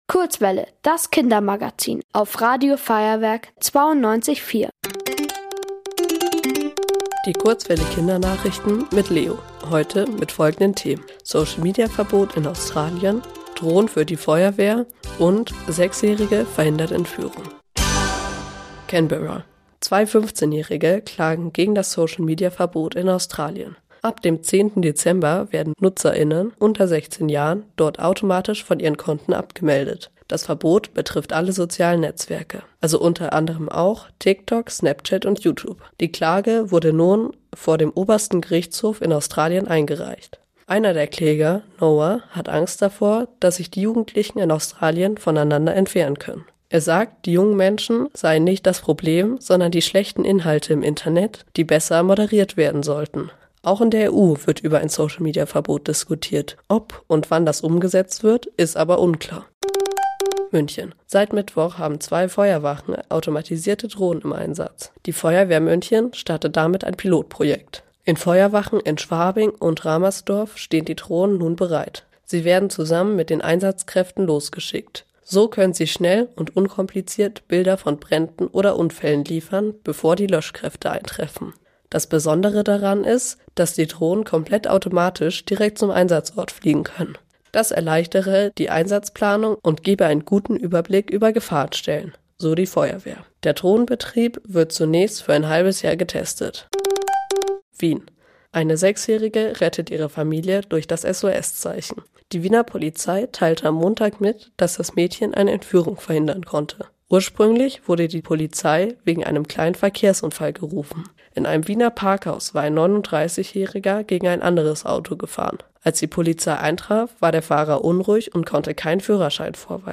Die Kurzwelle Kindernachrichten vom 06.12.2025